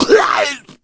Index of /cstrike/sound/RA_Zmsounds/Pain
Hunter_PounceCancel_02.wav